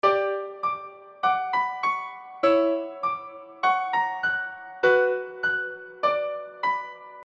空间旋律
描述：太空旋律，不和谐的序列。
Tag: 旋律 好奇 合成器 铃声 旋转 循环 顺序 空间 噪音 不和谐 声音